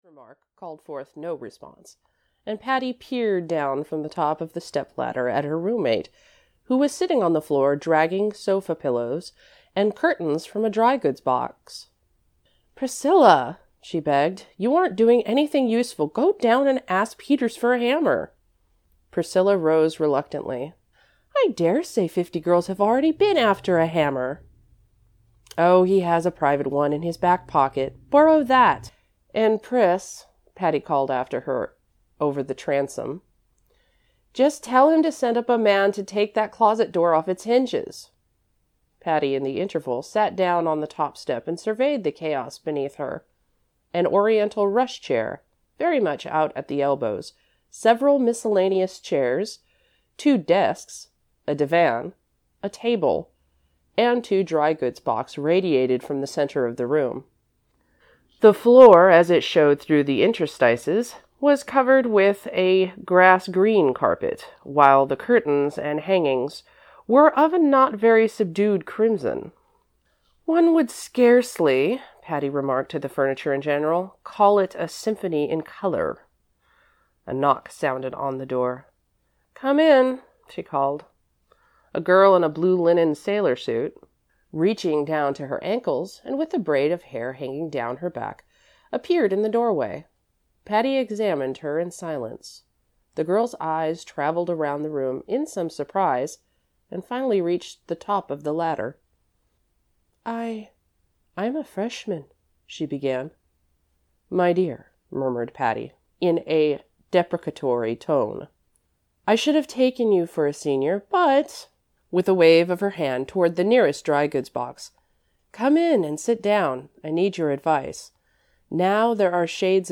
Audio knihaWhen Patty Went to College (EN)
Ukázka z knihy